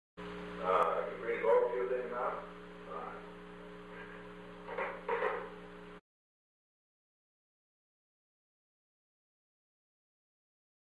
Secret White House Tapes
Conversation No. 410-12
Location: Executive Office Building
The President talked with H. R. (“Bob”) Haldeman.